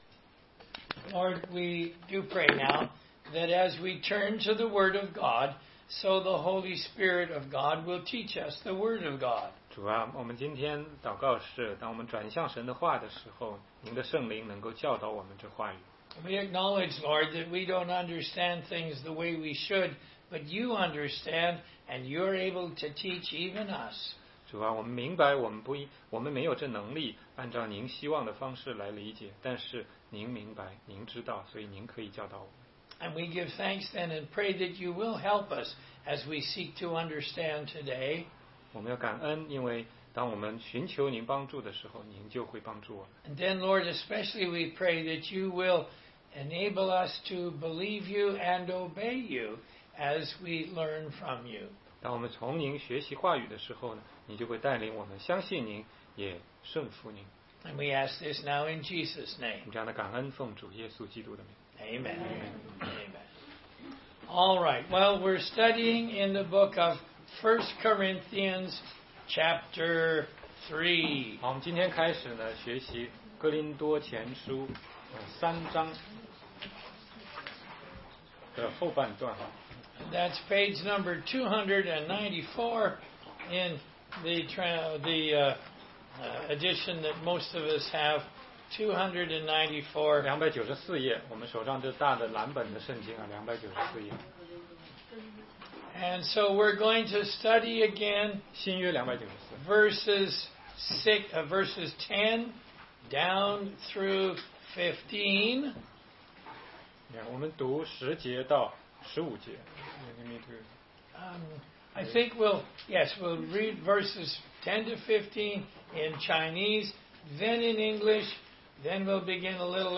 16街讲道录音 - 哥林多前书3章10-22节-建造房屋的材料续